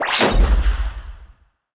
Wep Grenade Explode Sound Effect
Download a high-quality wep grenade explode sound effect.
wep-grenade-explode.mp3